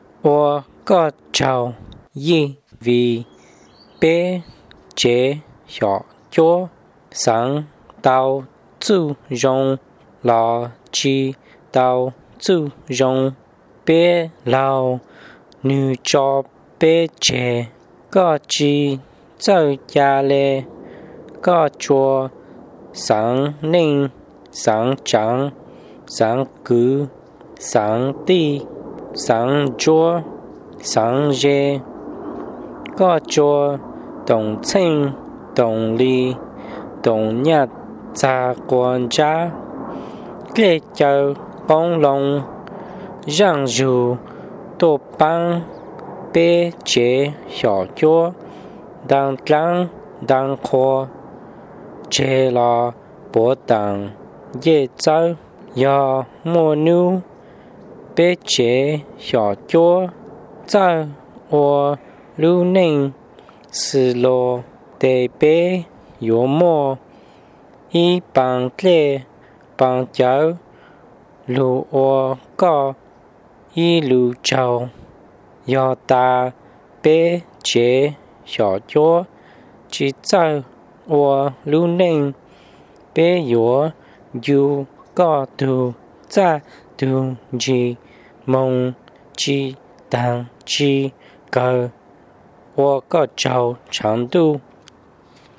This is the hymn that is recited to the person making the donation.
( pronunciated version )